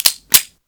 PIANETA GRATIS - Audio/Suonerie - Armi - Pagina5